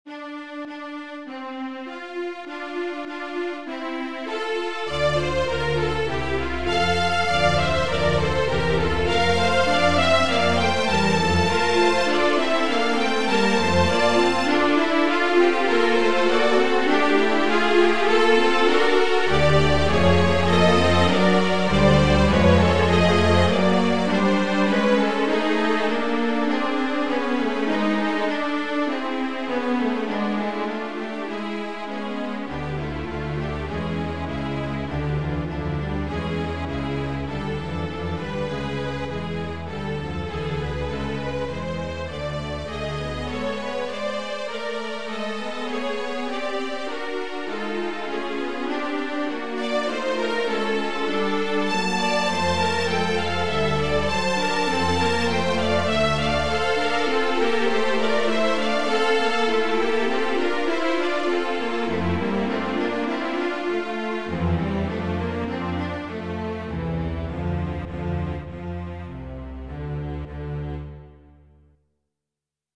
Esope reste ici et se repose miniature canon/palindrome, things not quite as they seem at first.
canon-palindrome-for-string-quartet.mp3